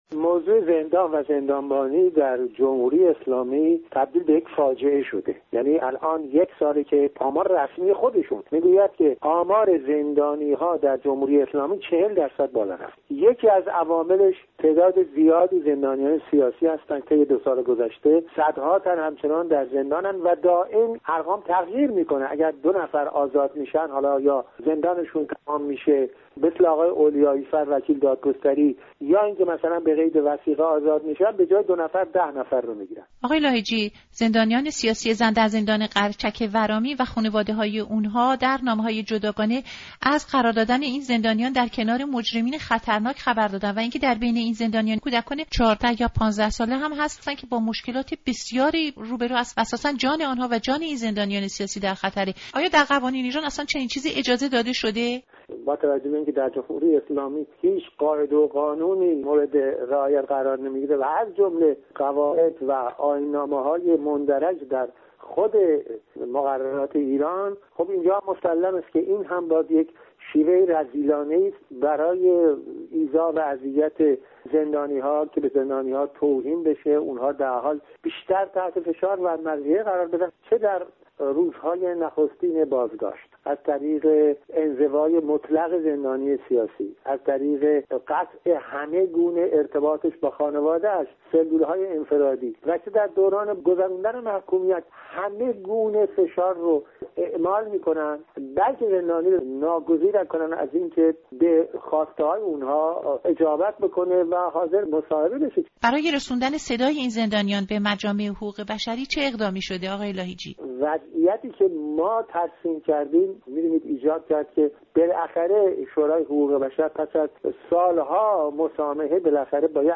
گفت‌وگو با عبدالکریم لاهیجی در مورد وضعیت نگهداری زنان زندانی در قرچک